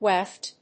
/wéft(米国英語)/